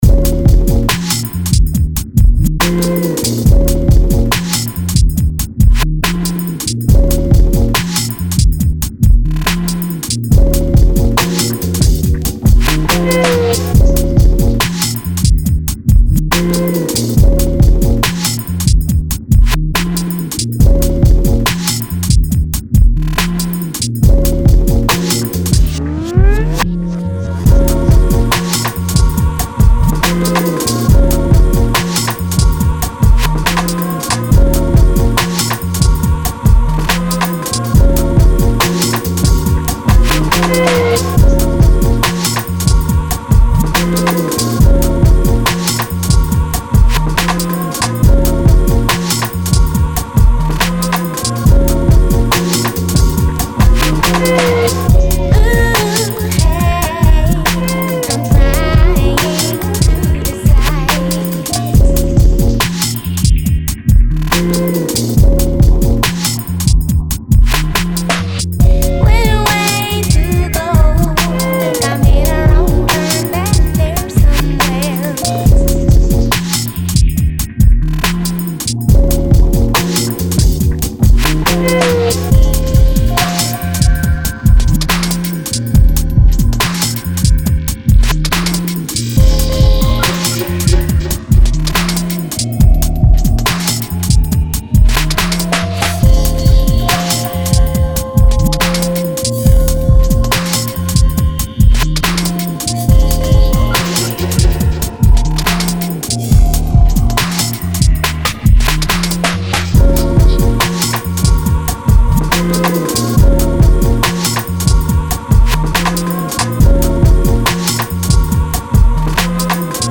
Le duo sud africain
remix très dubstep